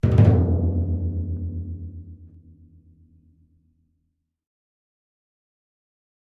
Timpani, (Hands) Deep Reminder, Type 1 - Short Ascending Roll